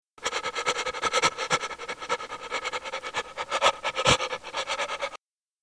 cry-of-fear-heavy-breath.mp3